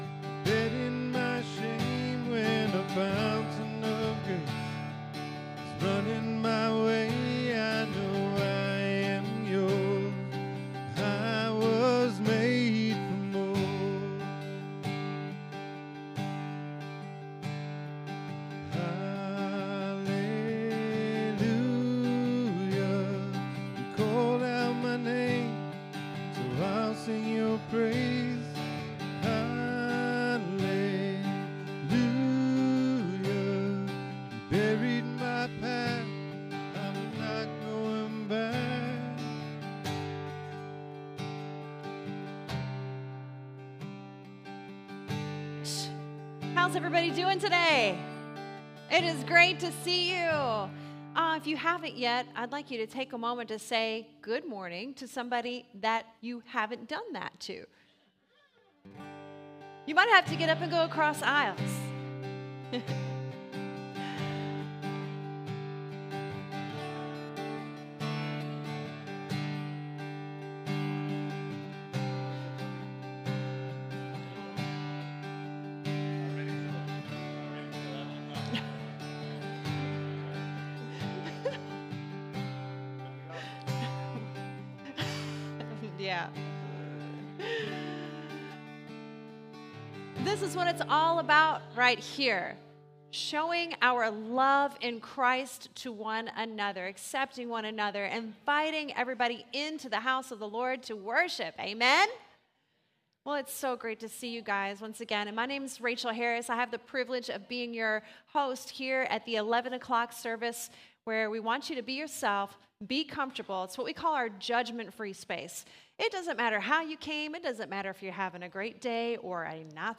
Download Download Reference Galatians 6:1-10 Sermon Notes Click Here for Notes 241124.pdf SERMON DESCRIPTION The apostle Paul has laid the foundation of grace and now he calls us to live a life that is defined by it.